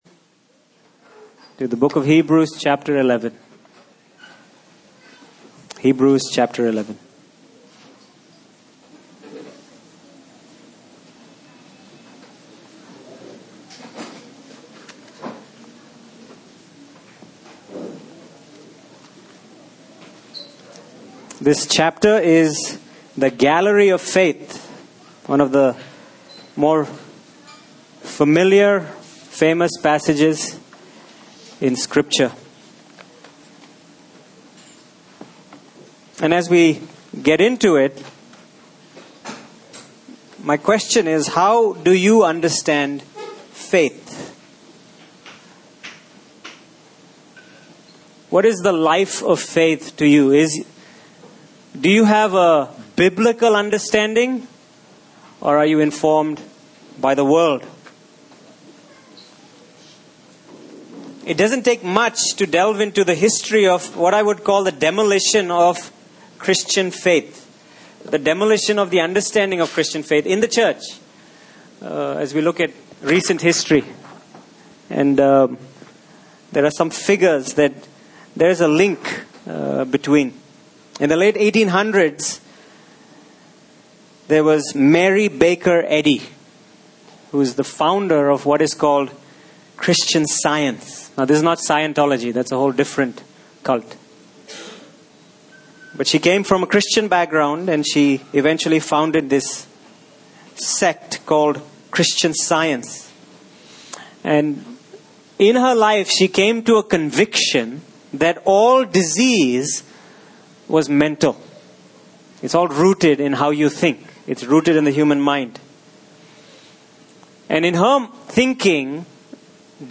Passage: Hebrews 11:1-3 Service Type: Sunday Morning